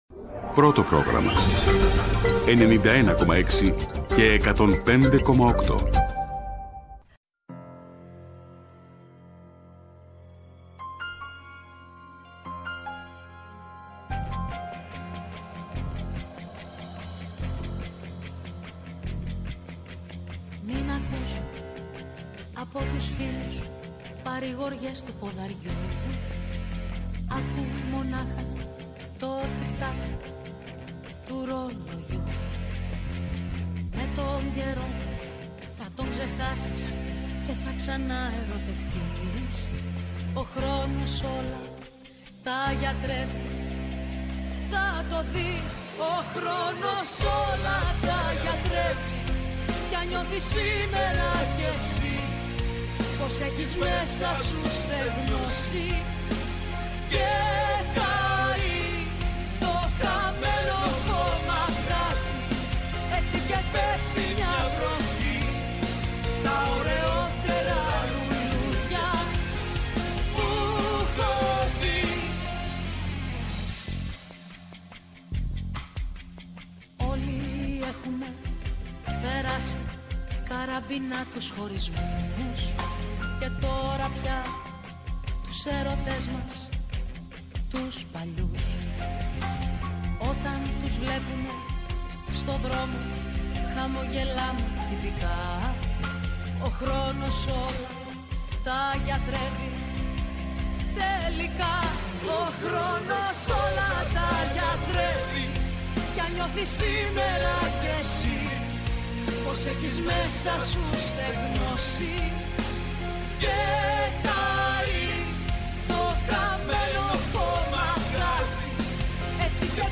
Ένα μαγκαζίνο που ανοίγει παράθυρο στην Ελλάδα και τον κόσμο και δίνει μικρόφωνο στους πρωταγωνιστές ή σε εκείνους που ξέρουν να διαβάζουν ανάμεσα στις γραμμές των γεγονότων. Ενημέρωση με πολλή μουσική και σαββατοκυριακάτικη διάθεση.